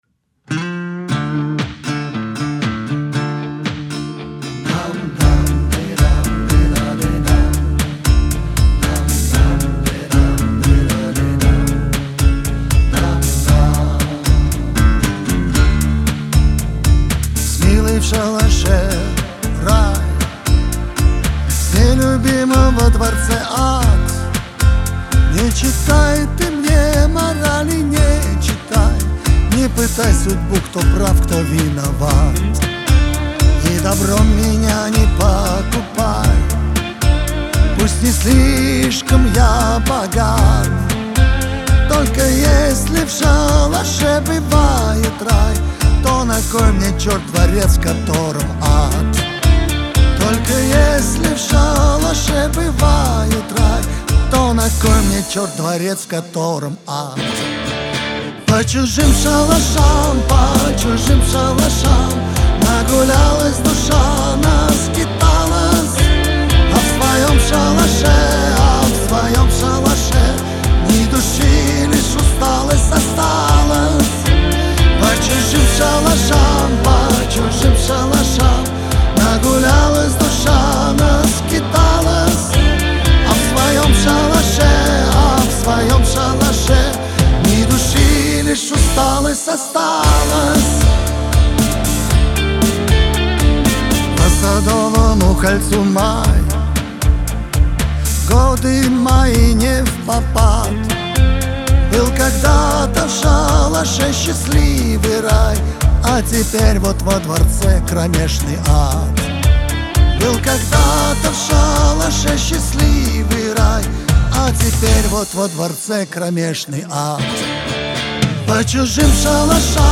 Песня записана на студии